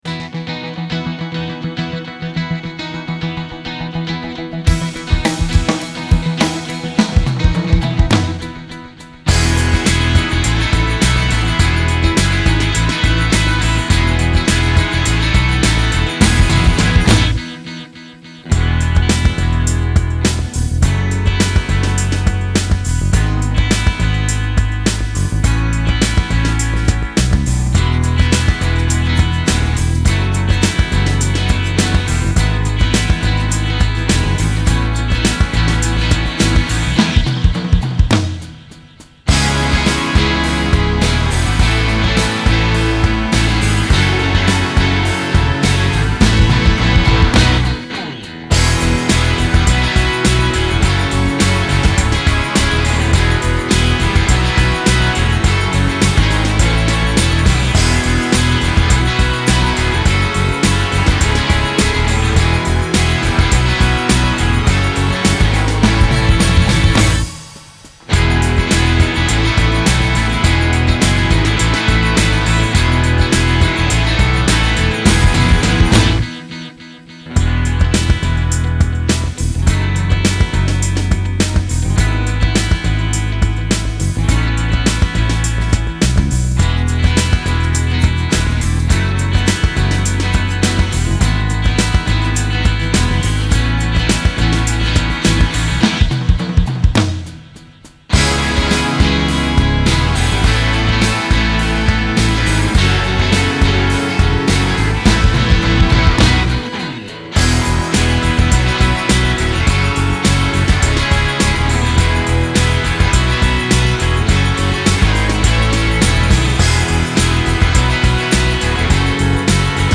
rockig, fröhlich,
kräftig
Tempo 104 4/4 E